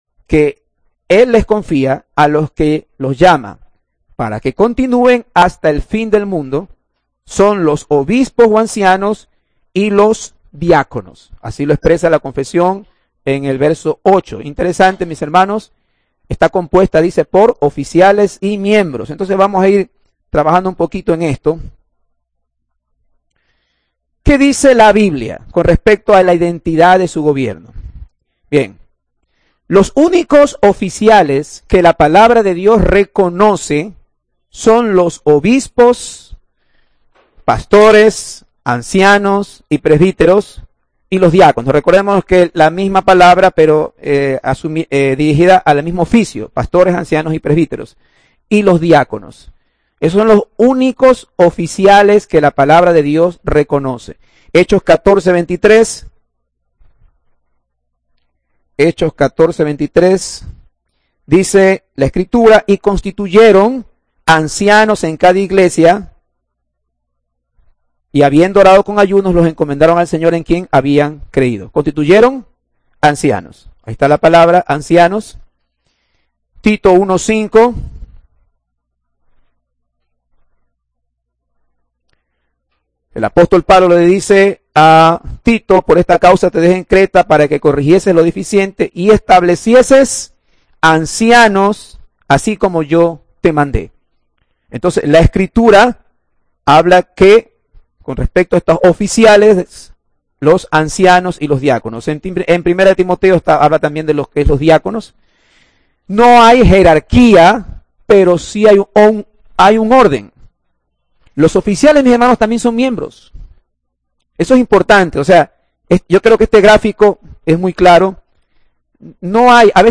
Audio del sermón